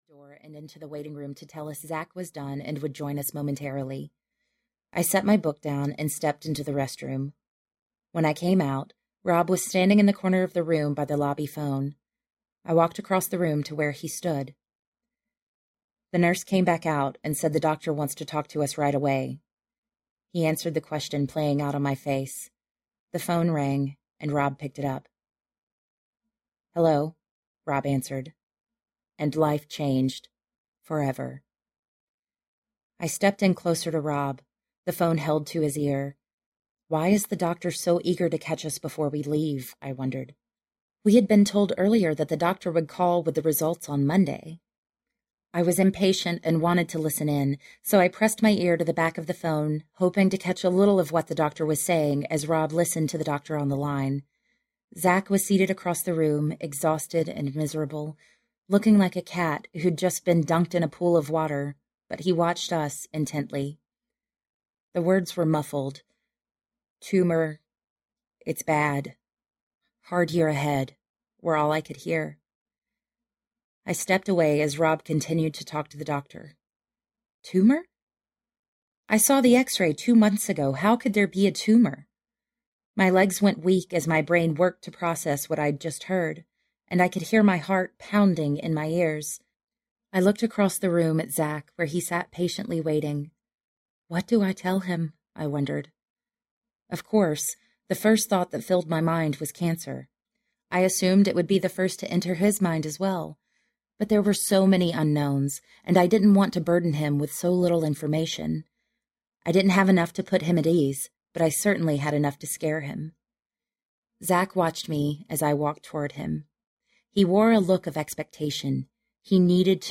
Fly a Little Higher Audiobook
Narrator
9.25 Hrs. – Unabridged